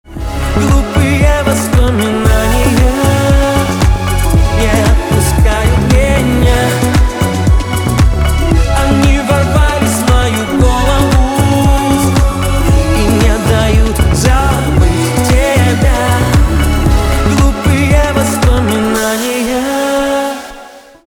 поп
грустные
гитара